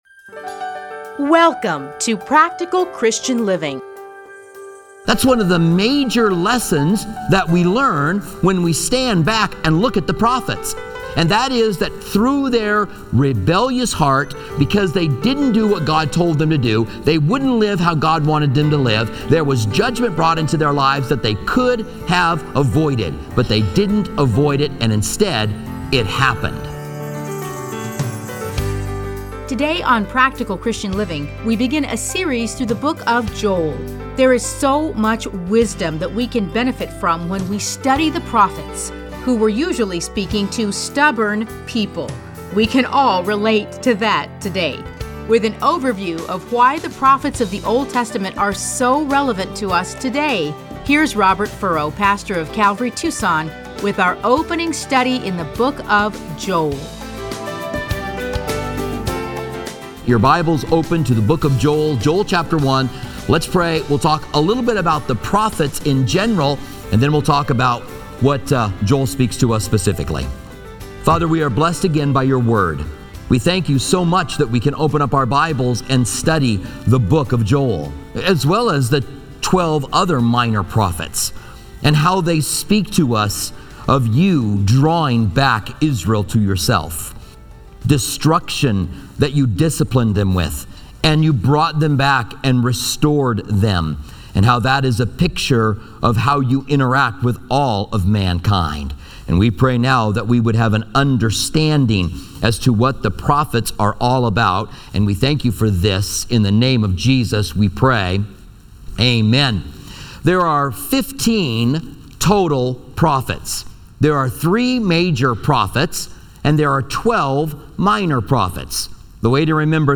Listen to a teaching from Joel 1:1-12.